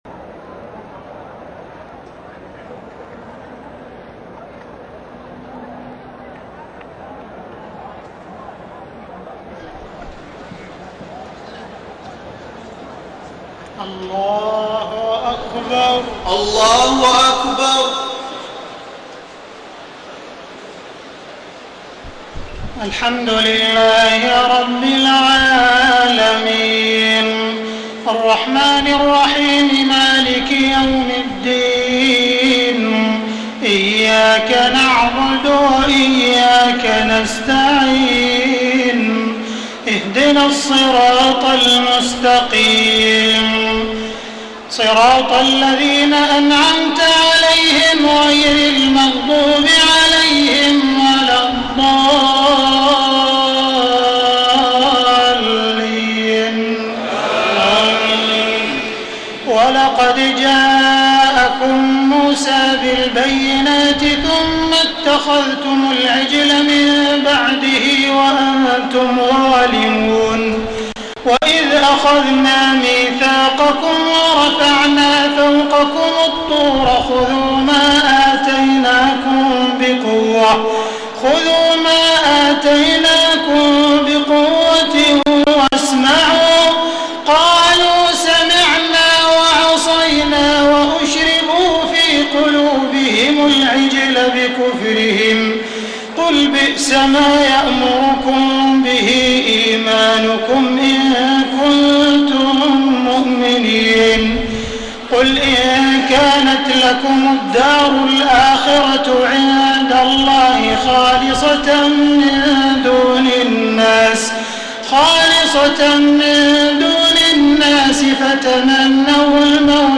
تهجد ليلة 21 رمضان 1433هـ من سورة البقرة (92-141) Tahajjud 21 st night Ramadan 1433H from Surah Al-Baqara > تراويح الحرم المكي عام 1433 🕋 > التراويح - تلاوات الحرمين